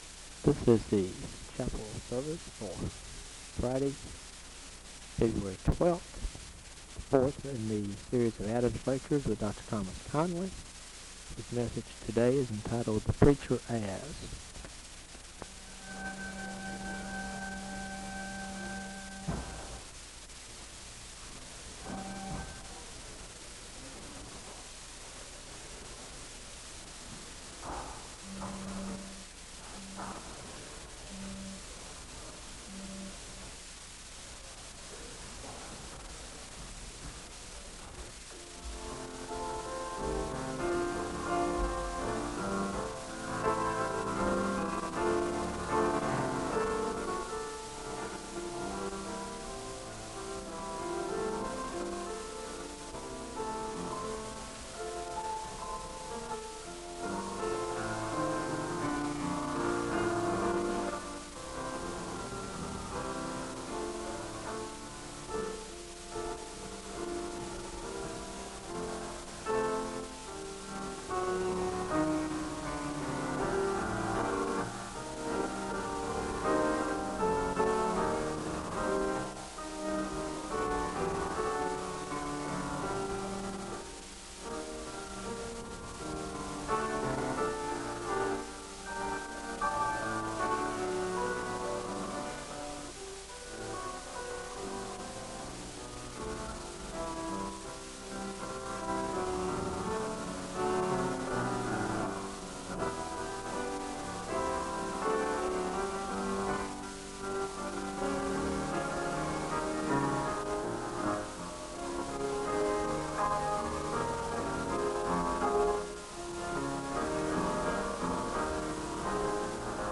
Audio quality is very poor.
The service begins with organ music (00:00-02:50).
The speaker gives a word of prayer (02:51-04:18).
The choir sings the anthem (09:31-12:00).
Most of the lecture is inaudible (14:13-55:39). The service ends with a benediction (55:40-56:05).